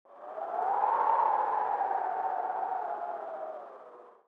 sfx_冷飕飕.wav